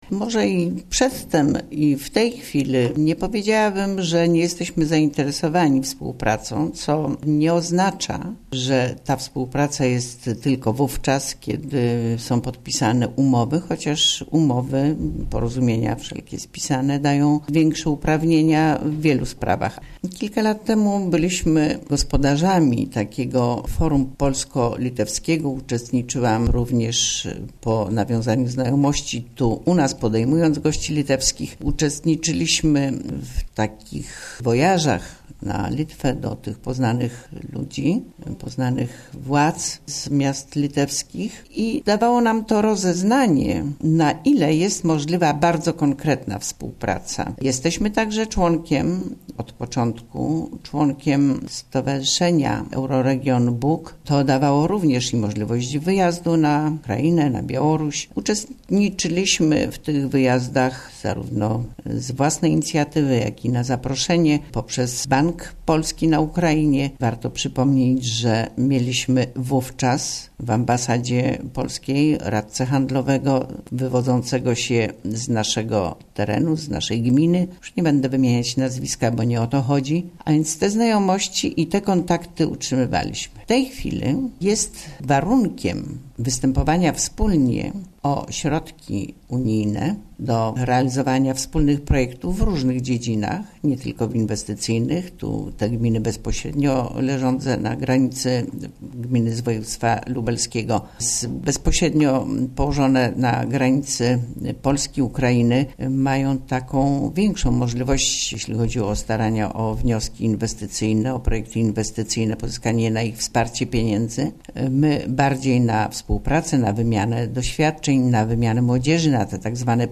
Wójt Gminy Łuków
Kazimiera Goławska